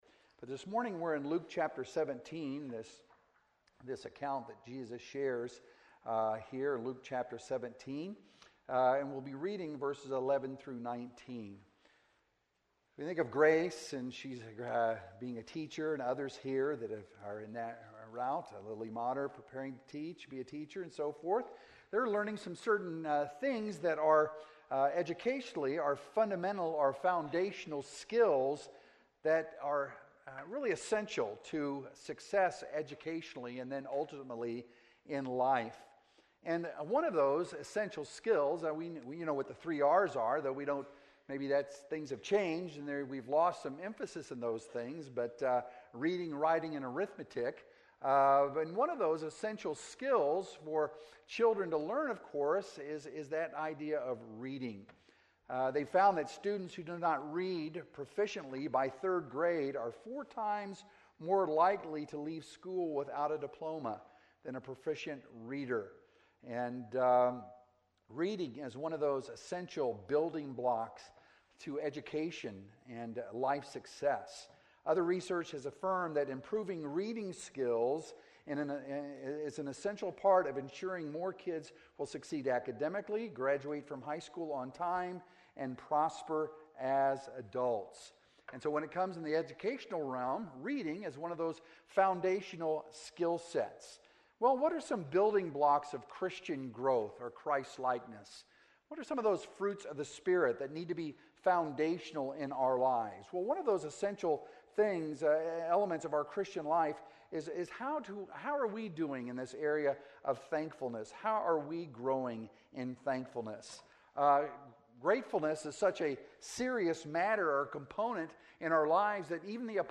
Sermons - Emmanuel Baptist Church
From Series: "Sunday Mornings"